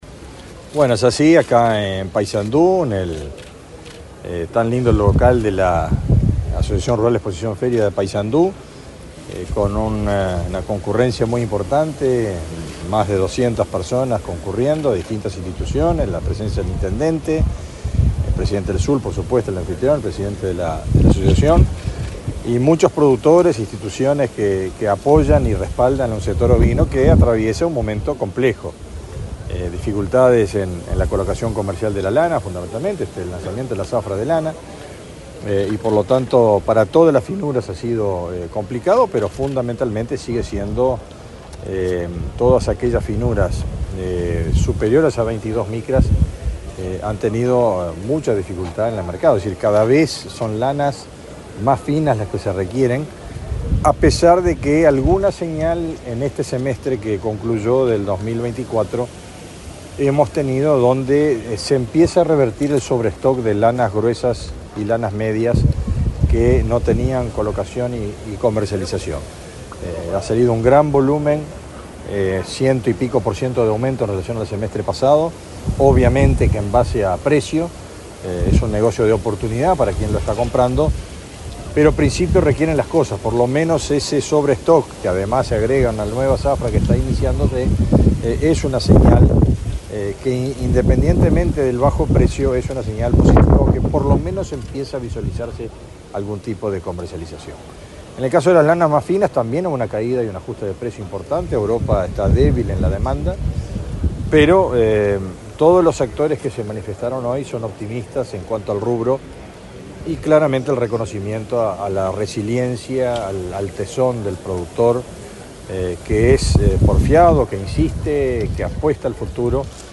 Declaraciones del ministro de Ganadería, Fernando Mattos
El ministro de Ganadería, Fernando Mattos, dialogó con la prensa en Paysandú, luego de participar en el lanzamiento de la zafra ovina.